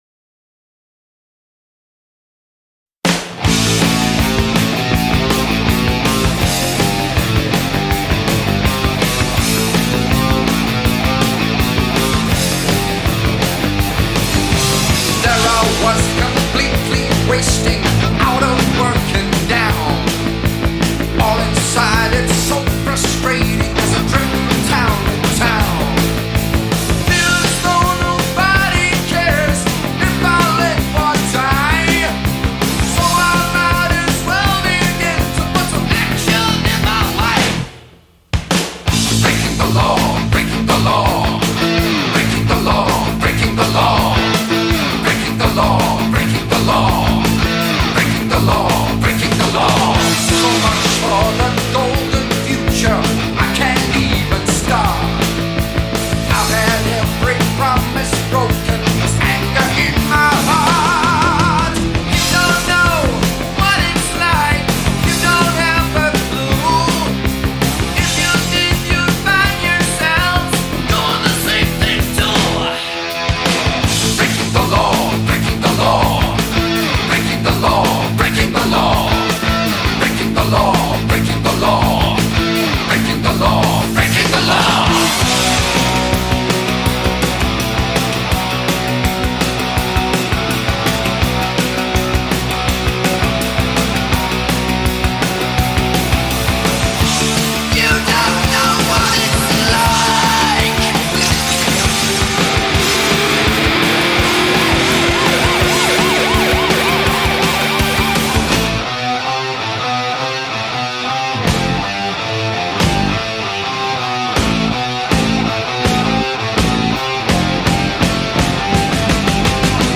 با ریف‌های گیتار و شعارهای سرودگونه
Heavy Metal